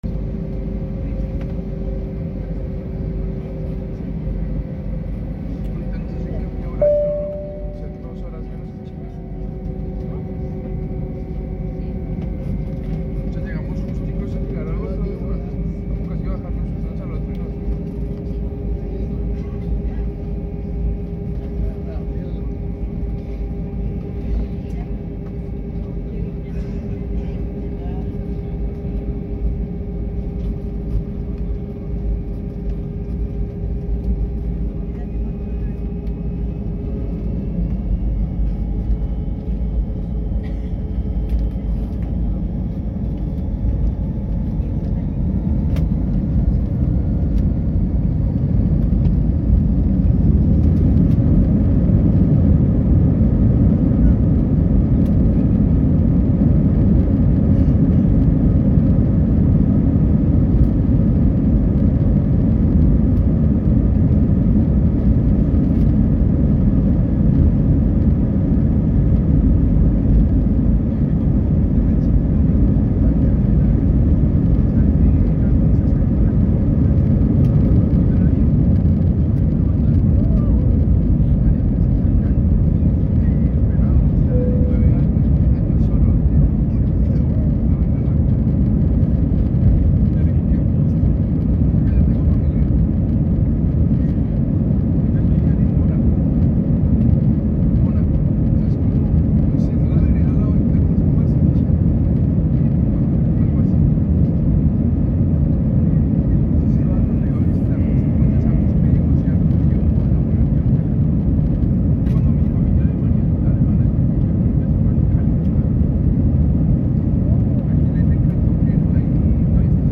Taking off from Buenos Aires